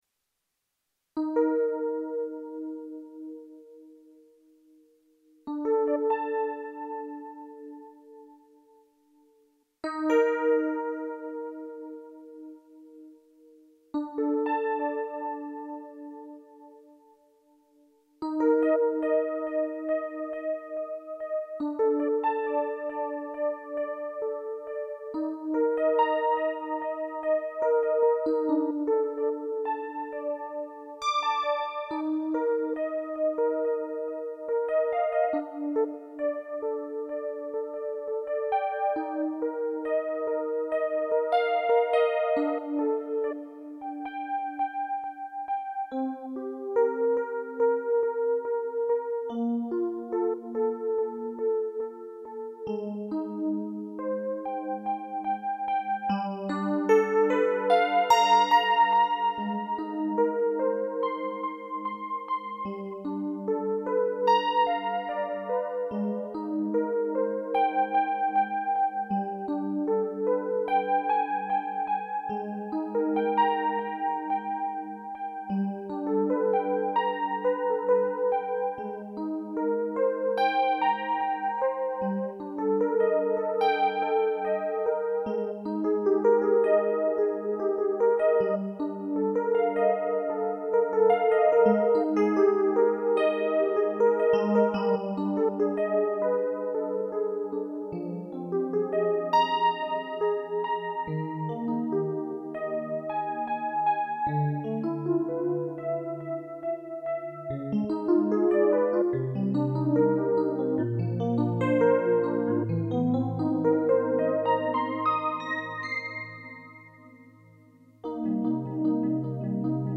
My Feelings This session was an intriguing blend of intention and spontaneity.
The music is a reflection of that journey—raw, real, and rooted in the here and now. *Use your headphones for better sound. 11th FeelYourself Download I’d love to hear your thoughts on how the session resonated with you.